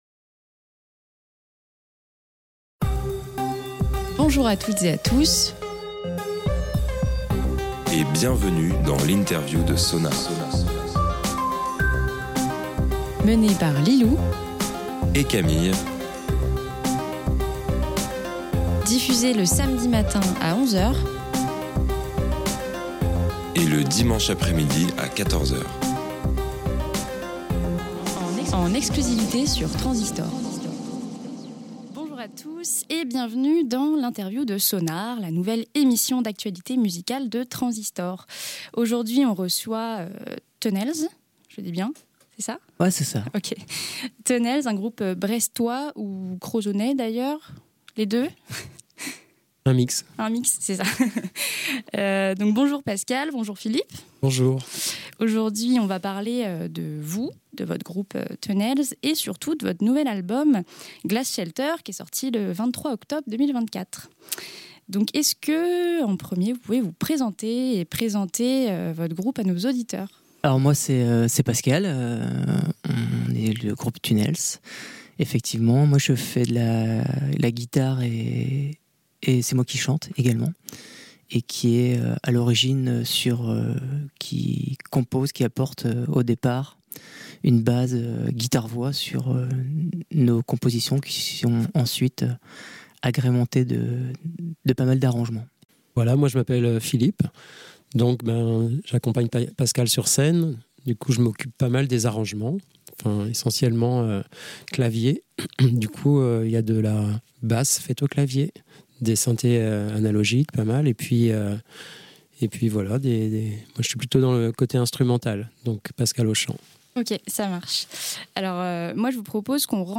SONAR x INTERVIEW - Tunnels